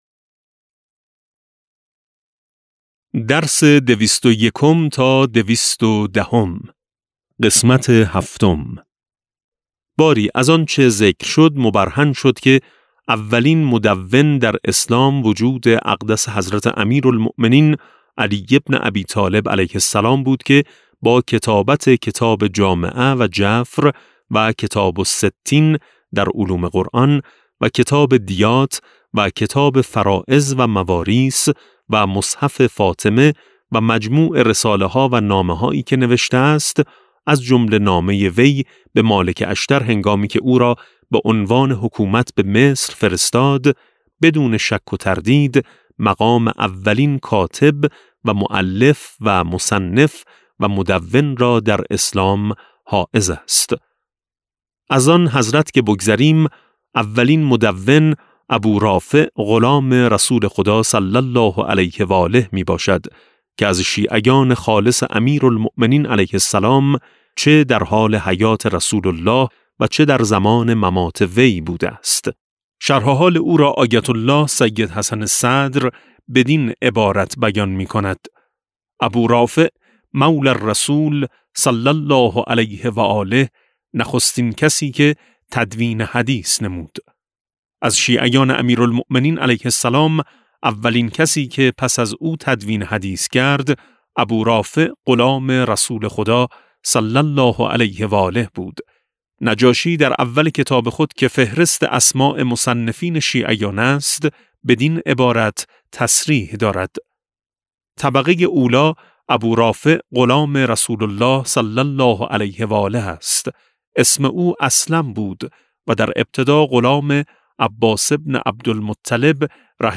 کتاب صوتی امام شناسی ج14 - جلسه15